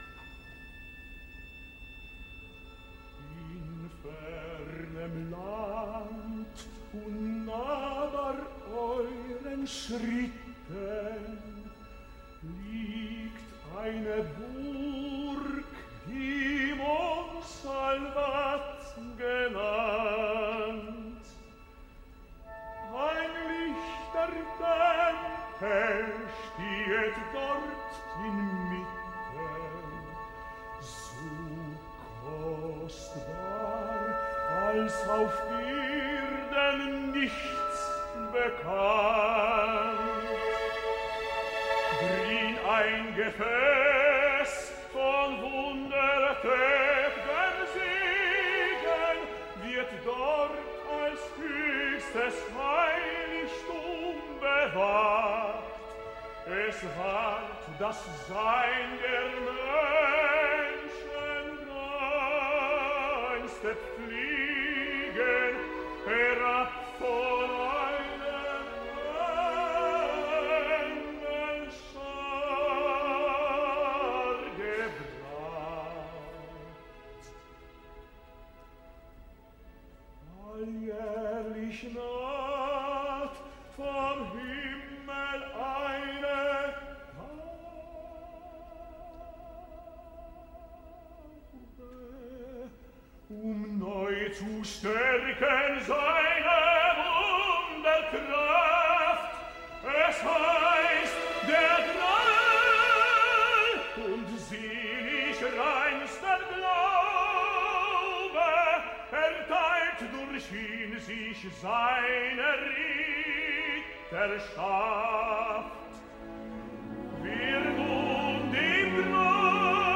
La darrera de les retransmissions radiofòniques en directe del Festival de Bayreuth 2025 ha estat la primera representació del Lohengrin de l’1 d’agost, amb el màxim al·licient centrat en el rol protagonista del tenor polonès Piotr Beczala, que ja l’havia cantat en el Festival dels anys 2018 i 2019 i com aquest any, amb la direcció musical de Christian Thielemann i la producció de Yuval Sharon, si bé ja m’atreviria a dir d’entrada, que aleshores amb millors companys de viatge que ara.